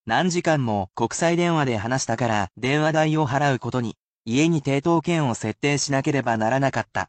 I am sure to repeat the main word(s) slowly, but I read the sentences at a natural pace, so do not worry about repeating after the sentences.
[casual speech]